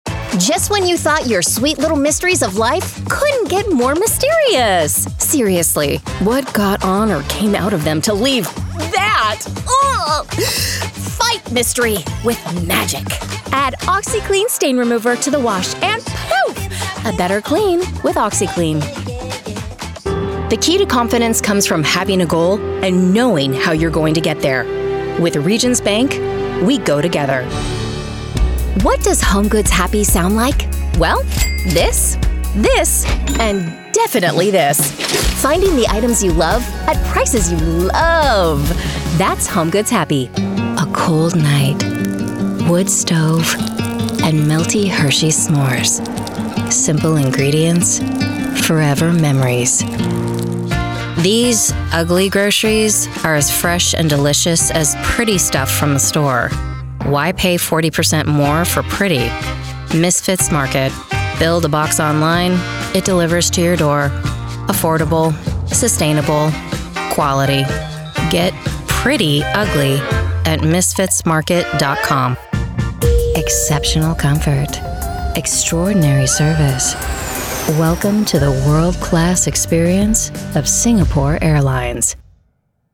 Australian Female Voice Over Artists, Talent & Actors
Adult (30-50) | Yng Adult (18-29)